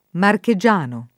marchegiano [ marke J# no ] → marchigiano